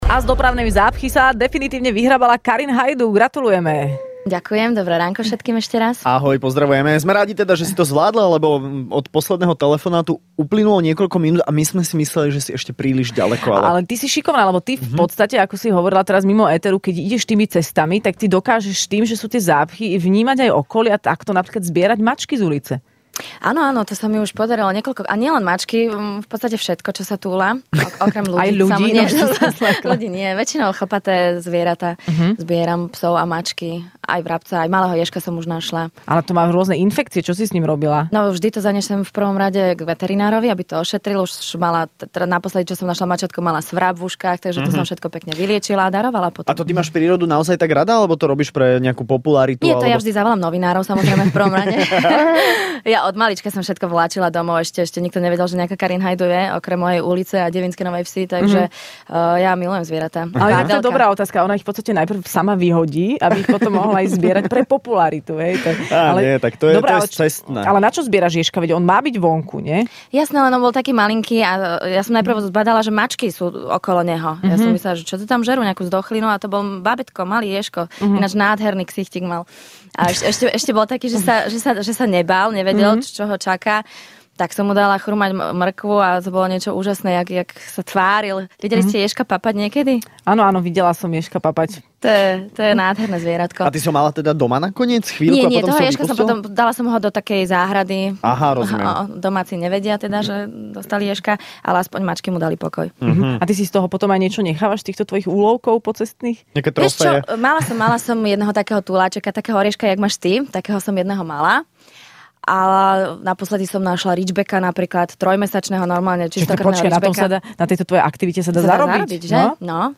V Rannej šou bola dnes hosťom herečka Karin Haydu.